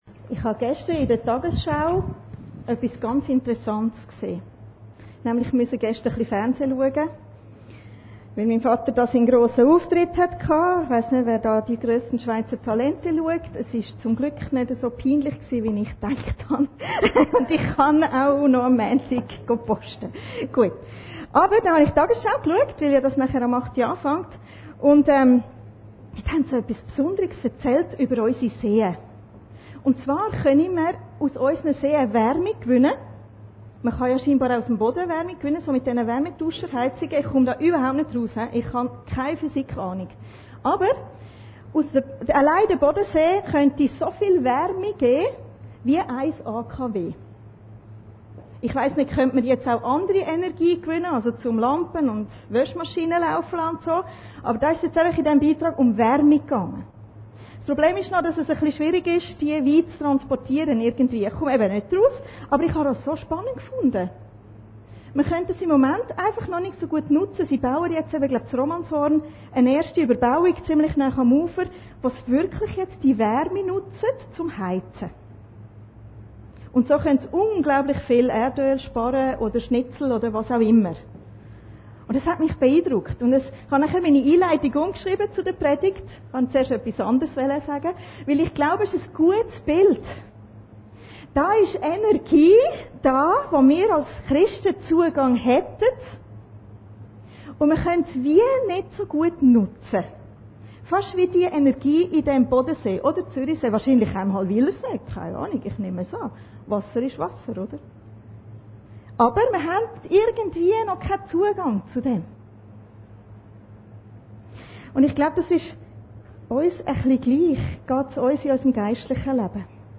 Predigten Heilsarmee Aargau Süd – Neue Dimensionen durch den Heiligen Geist